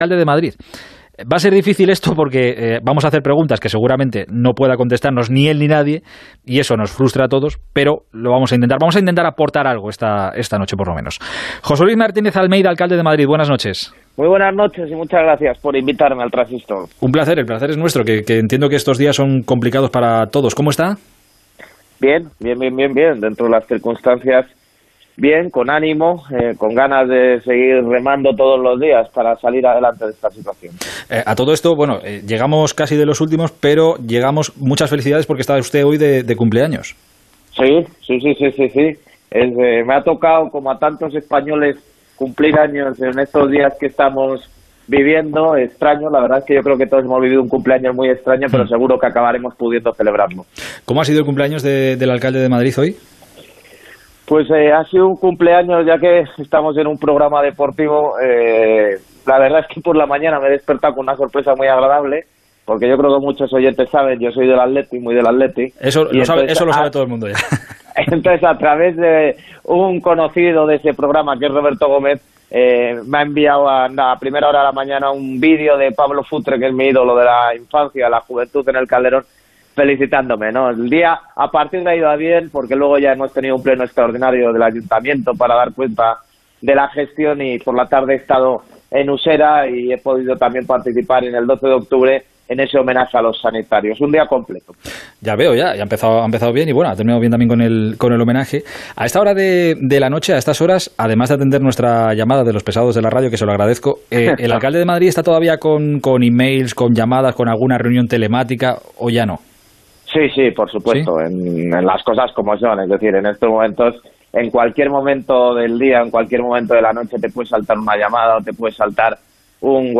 El alcalde de Madrid ha repasado la actualidad del coronavirus y en lo deportivo reconoce en los micrófonos de El Transistor de Onda Cero que "fue un error" que los aficionados del Atlético de Madrid viajaran a Liverpool